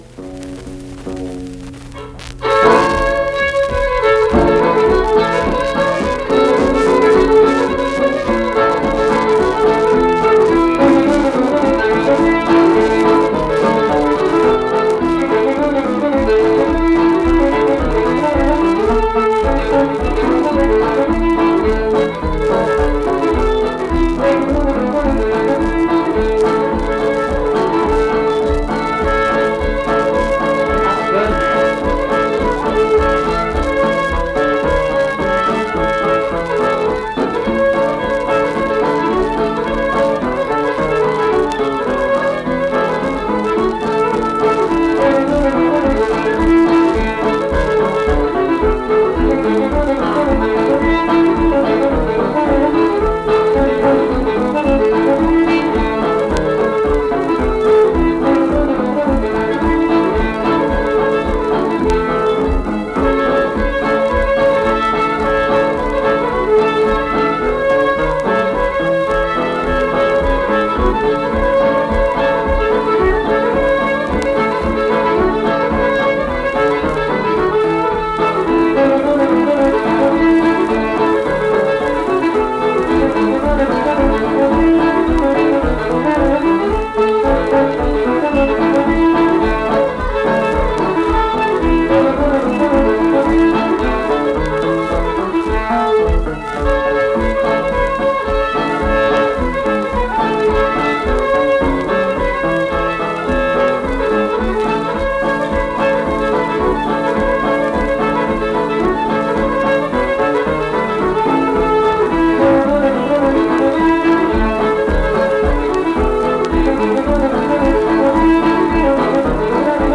Accompanied guitar and unaccompanied fiddle music performance
Instrumental Music
Guitar, Fiddle
Pocahontas County (W. Va.), Mill Point (W. Va.)